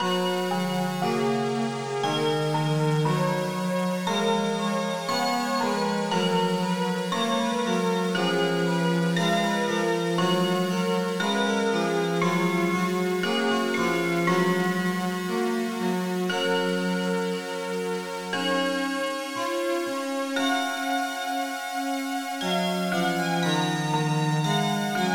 Synth Atmo + Crystal